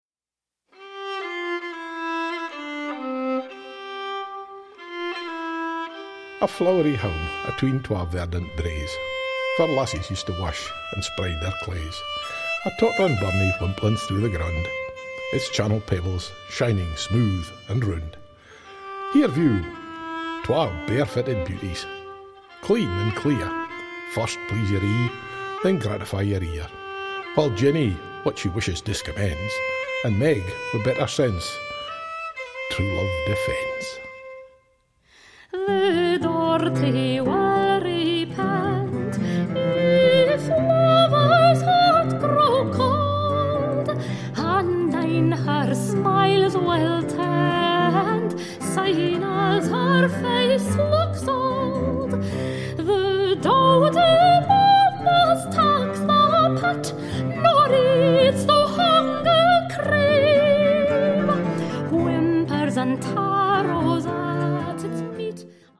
• Genres: Early Music, Opera